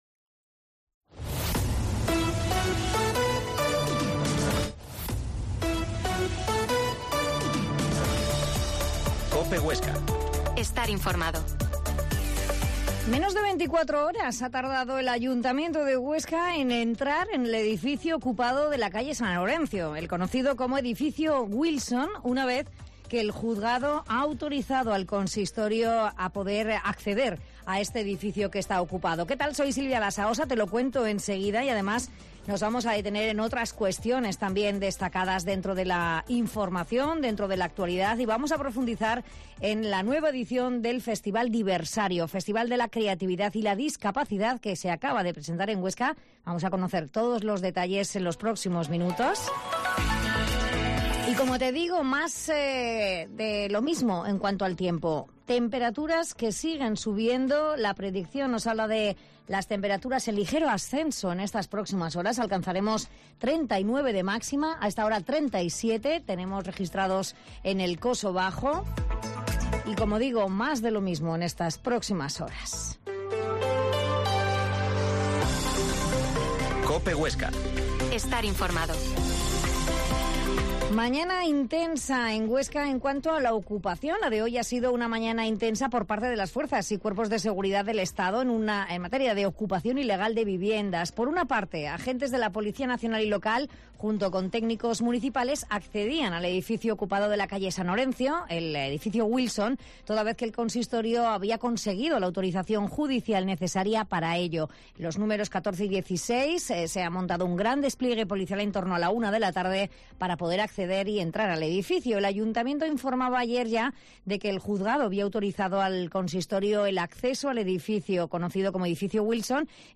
Mediodia en COPE Huesca 13.50 Reportaje sobre el festival "Diversario"